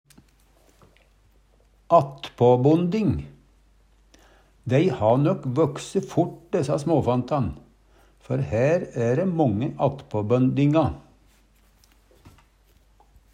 attpåbonding - Numedalsmål (en-US)
Høyr på uttala Ordklasse: Substantiv hankjønn Attende til søk